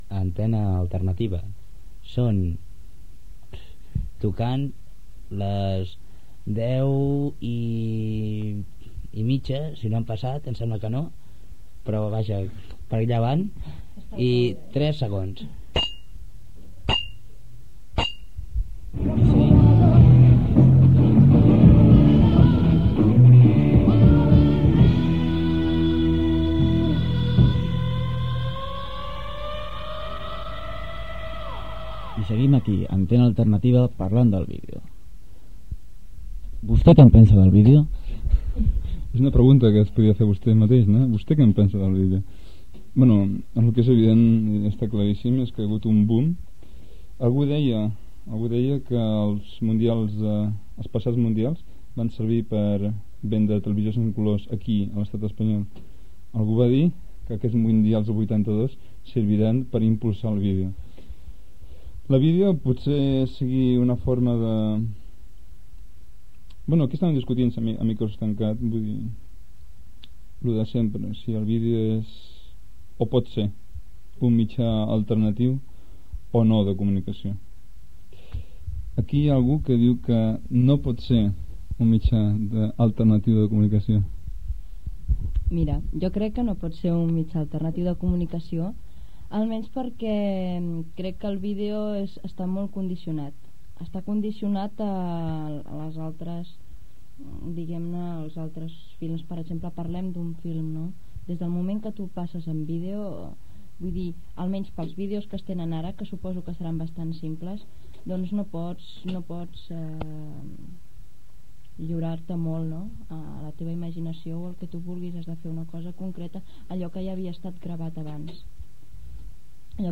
Identificació. Debat sobre el vídeo com a mitjà alternatiu.
Informatiu
FM